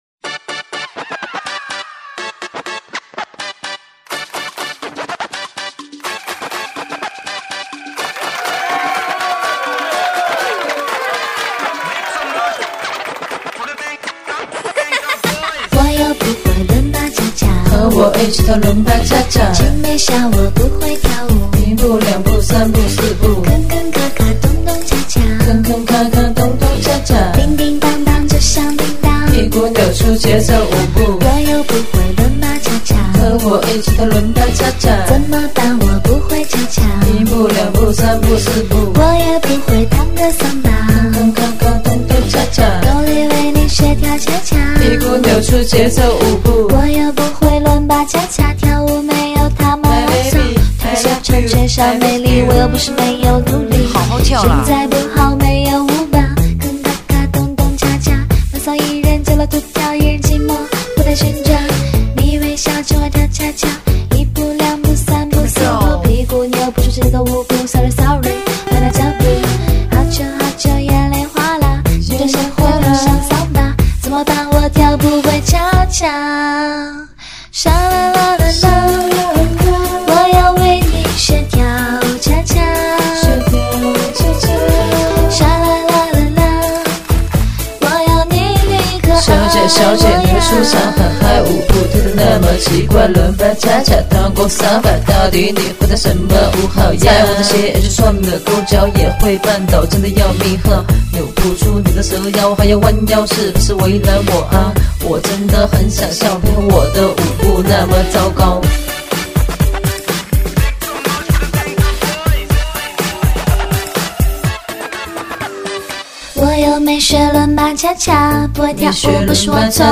[12/2/2010]【新年快乐】╔►新轻快说唱《♪为你而舞的恰恰♪》 激动社区，陪你一起慢慢变老！